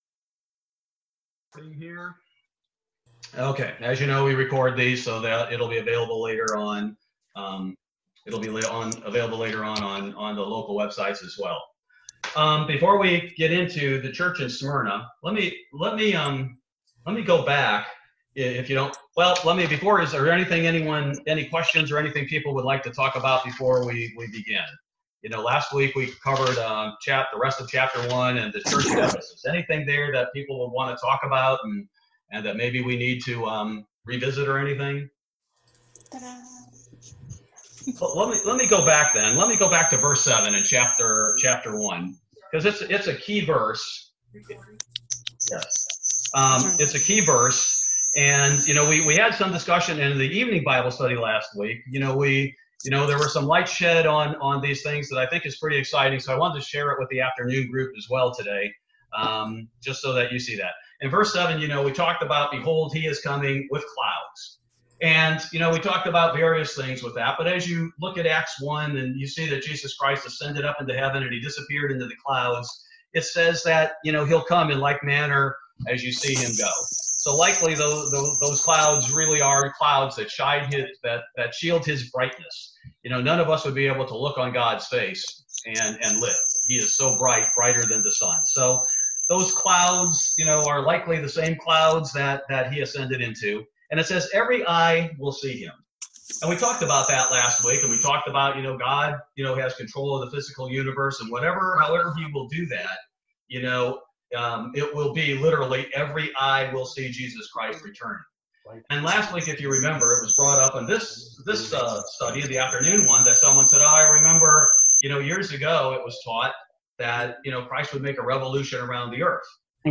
Bible Study July 8, 2020